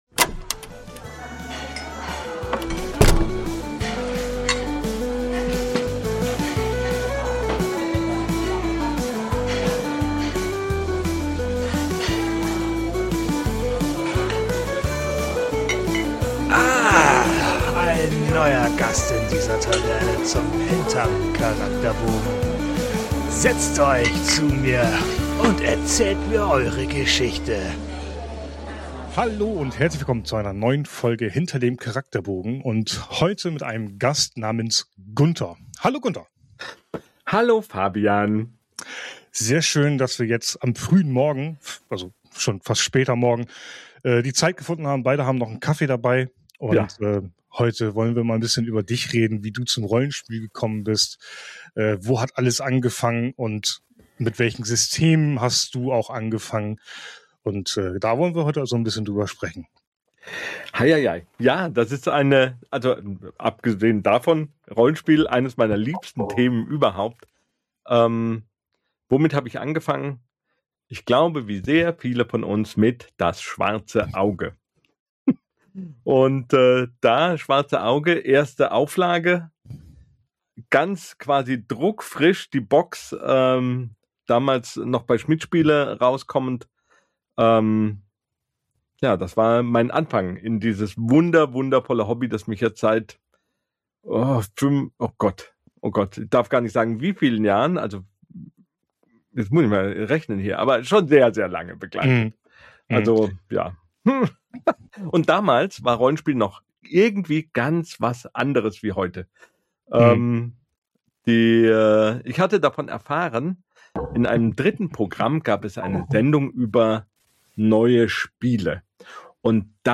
Sobald er anfängt, über Rollenspiele zu sprechen, sprudelt es nur so aus ihm heraus. Man muss ihn zwischendurch fast bremsen, damit er einmal Luft holt. Und das ist absolut nicht negativ gemeint – ganz im Gegenteil: Es ist großartig zu hören, mit wie viel Leidenschaft er dafür brennt!